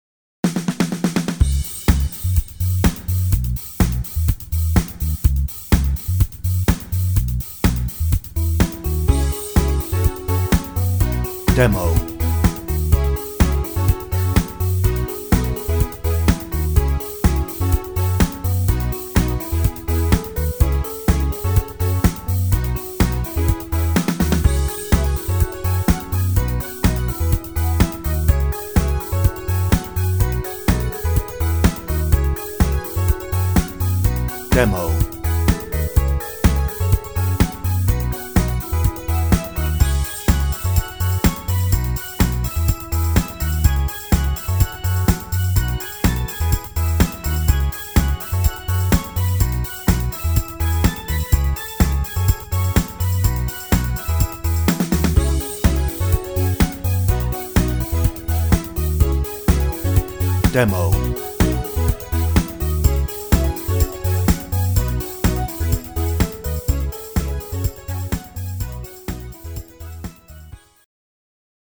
No ref vocal
Instrumental